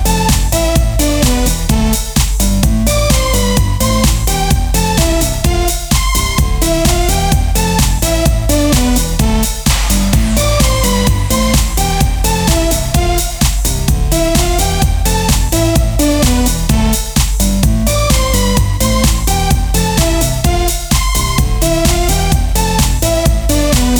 no BV Dance 3:37 Buy £1.50